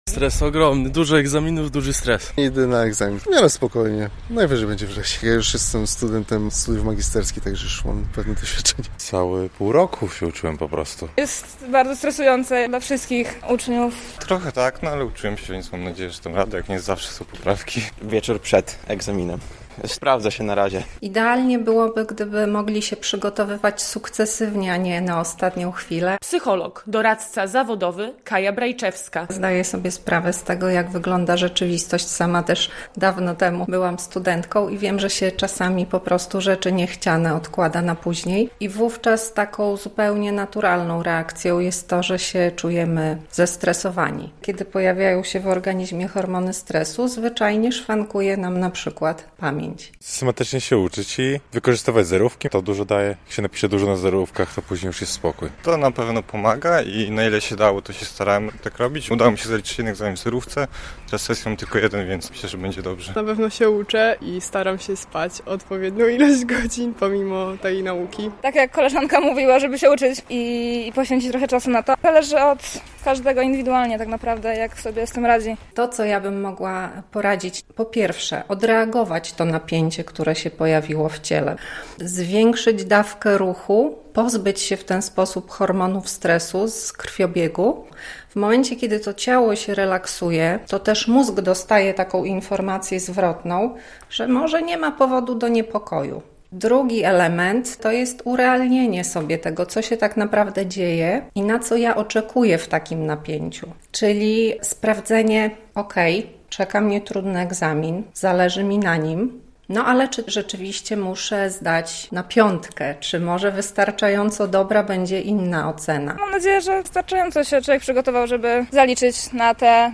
Jak przygotować się do sesji poprawkowej? - relacja